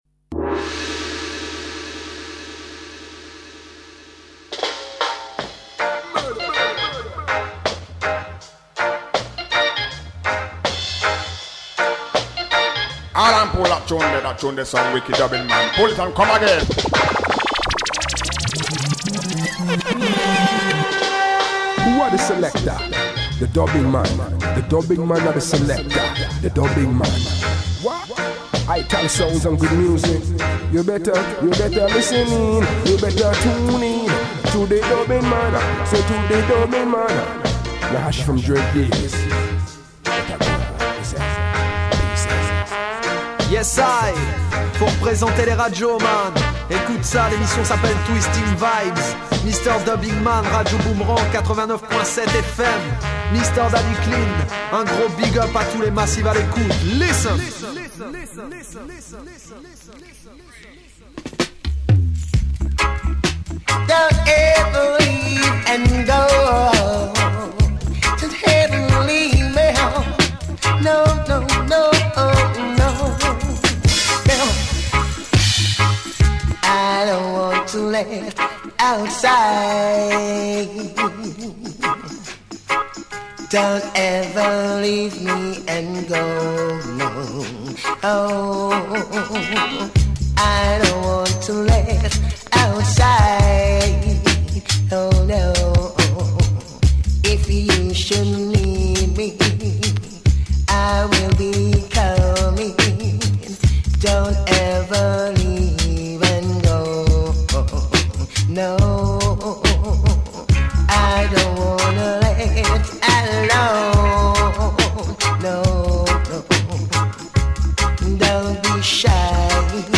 radio reggae dub show